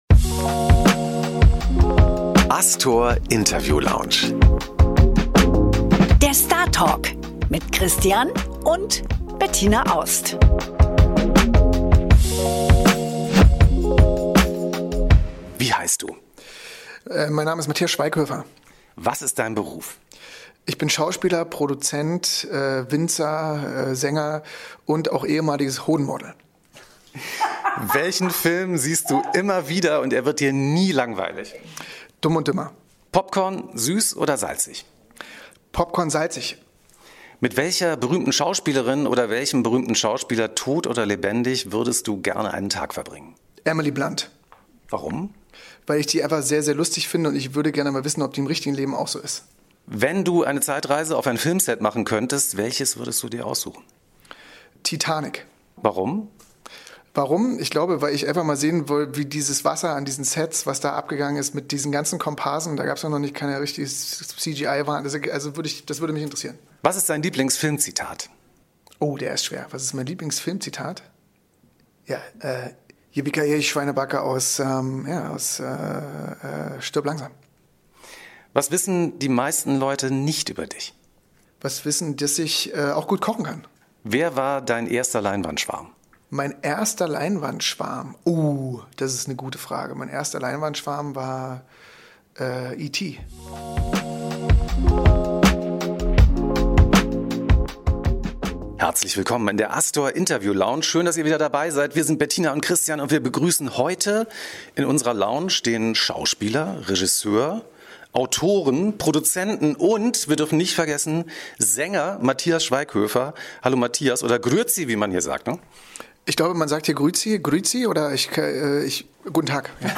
#11 – Matthias Schweighöfer – Über „Das Leben der Wünsche“, sein neues Ich, seinen Freund Tom Cruise und Partnerschaft auf Augenhöhe. ~ ASTOR INTERVIEW LOUNGE
Zu Gast im Podcast: Matthias Schweighöfer!